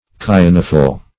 kyanophyll - definition of kyanophyll - synonyms, pronunciation, spelling from Free Dictionary Search Result for " kyanophyll" : The Collaborative International Dictionary of English v.0.48: Kyanophyll \Ky*an"o*phyll\, n. (Bot.)
kyanophyll.mp3